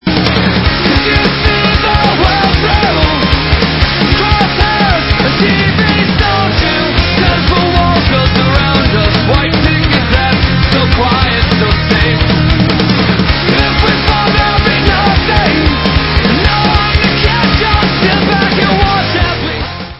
Americká punkrocková kapela